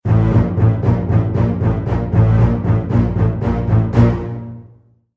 attention-danger.mp3